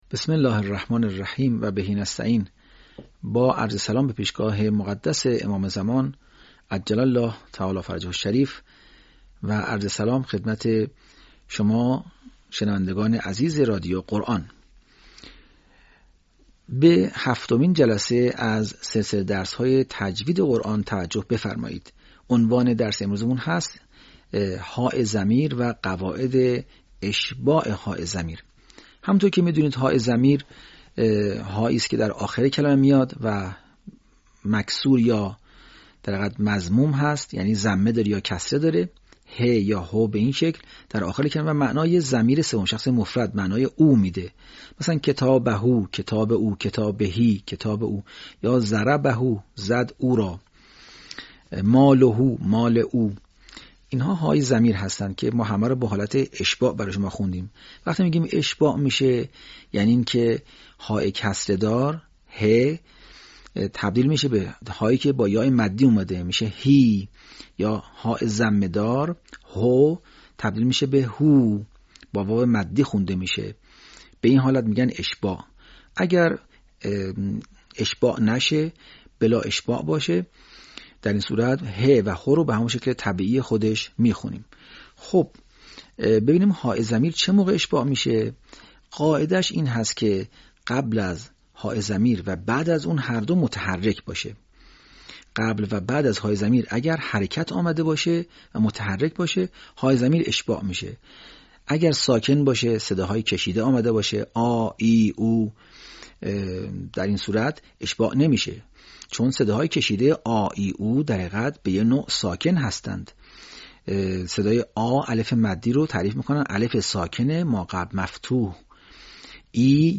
به همین منظور مجموعه آموزشی شنیداری (صوتی) قرآنی را گردآوری و برای علاقه‌مندان بازنشر می‌کند.
آموزش تجوید